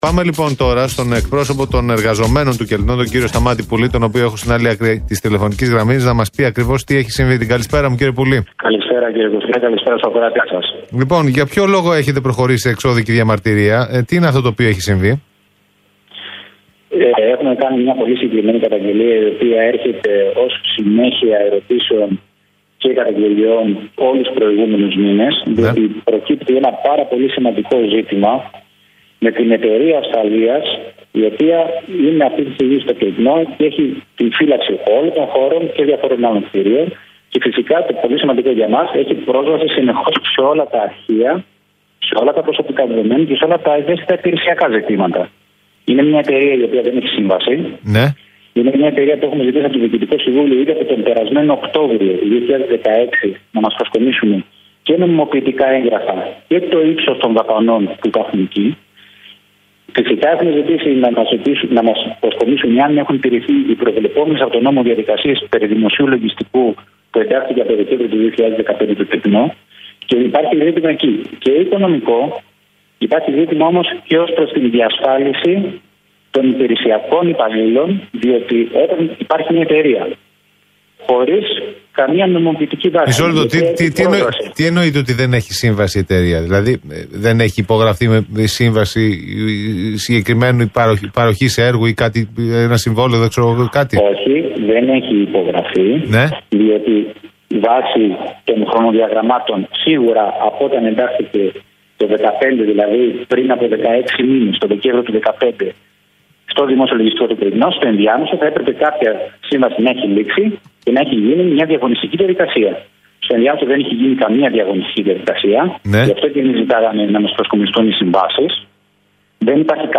ο οποίος μίλησε στο ραδιόφωνο του Alpha 98,9 καλεσμένος στην εκπομπή “Μεσημεριανό Ρεπορτάζ”